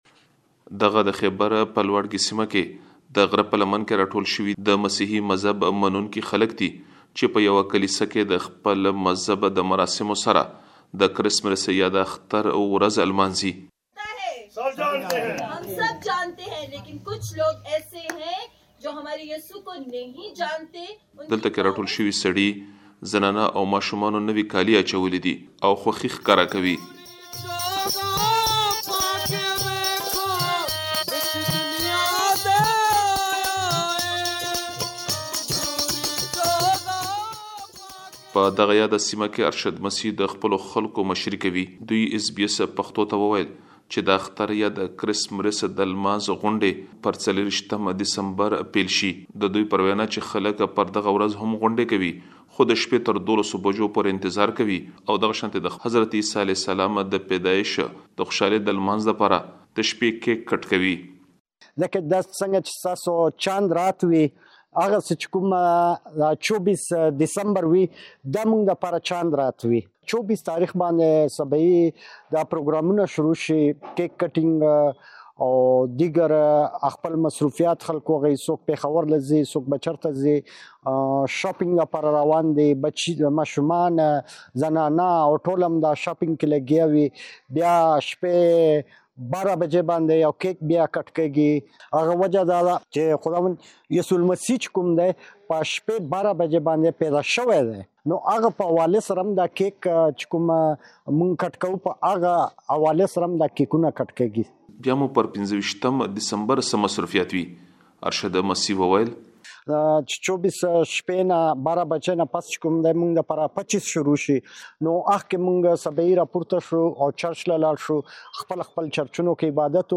له خیبر پښتونخوا څخه یو رپوټ را استولی دی